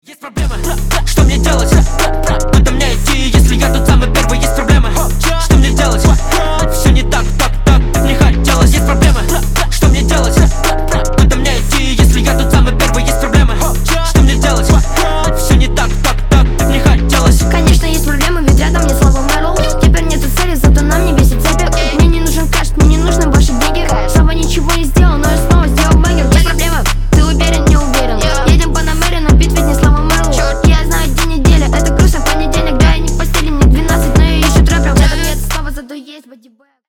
Рэп и Хип Хоп
ритмичные